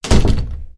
橱柜吱嘎声包 " 吱嘎声 01
描述：橱柜门上的一些吱吱作响的系列中的一个。用AT4021话筒录入改良的马兰士PMD661，用Reason编辑。
标签： 厨房 开放 吱吱 关闭 橱柜 弗利
声道立体声